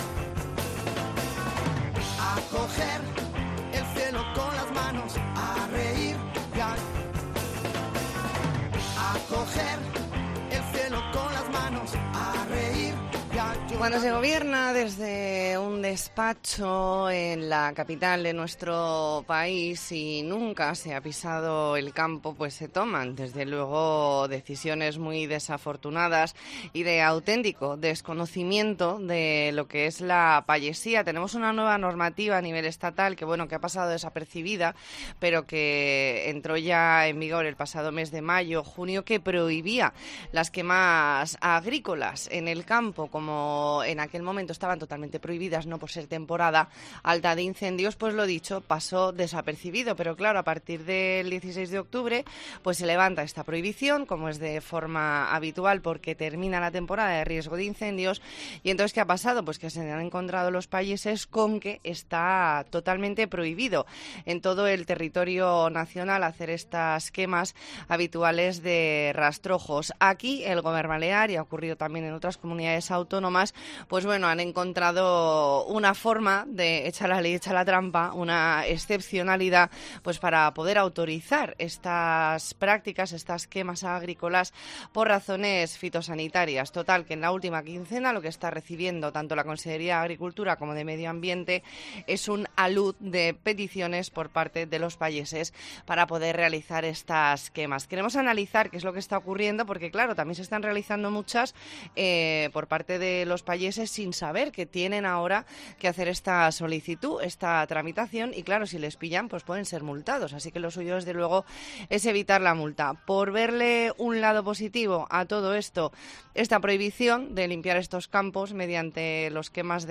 Entrevista en La Mañana en Baleares, 31 de octubre de 2022.